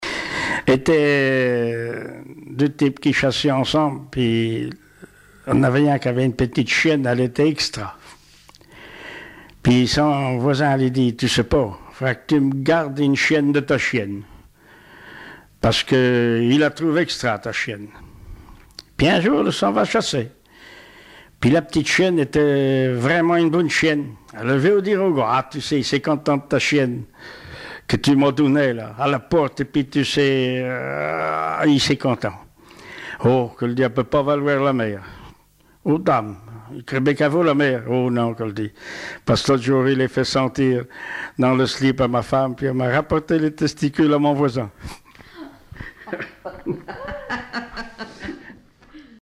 Langue Patois local
Genre sketch
Catégorie Récit